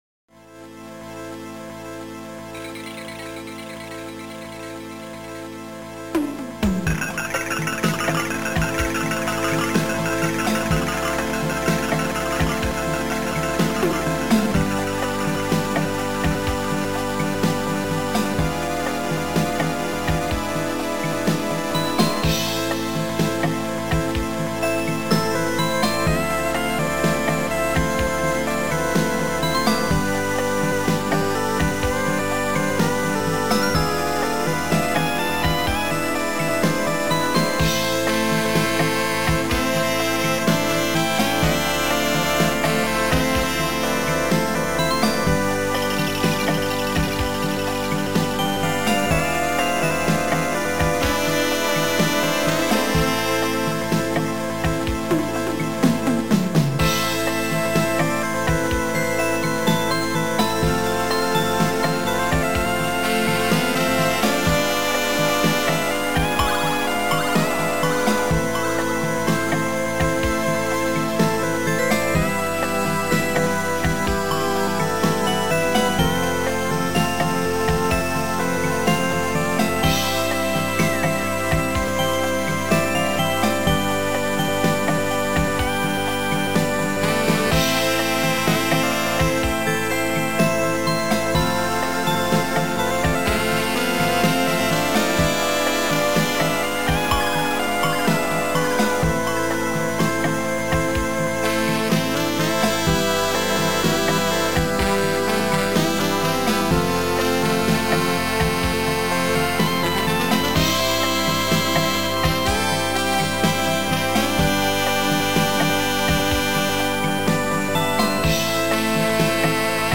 Sound Format: Noisetracker/Protracker
Sound Style: Sorrow